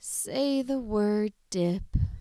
HAS-Corpus / Audio_Dataset /sad_emotion /1754_SAD.wav
1754_SAD.wav